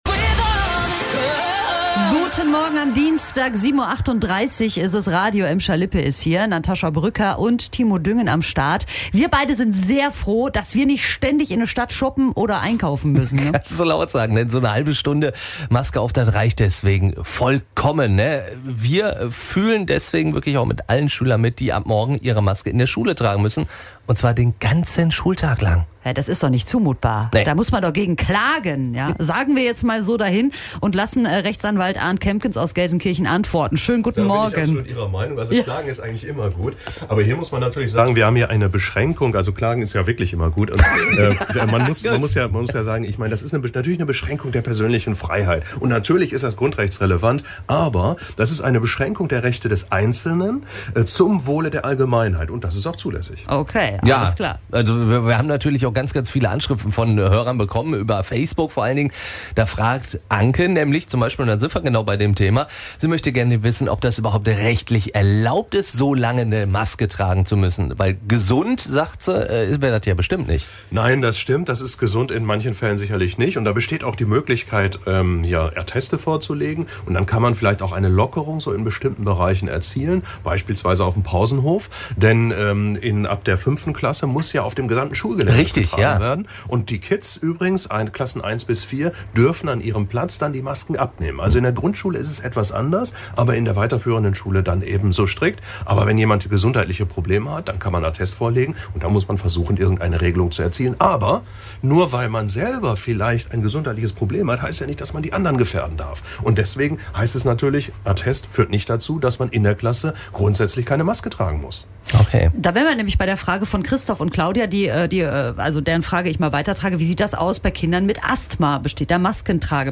Anwalt beantwortet Fragen zu Maskenpflicht in Schule - Radio Emscher Lippe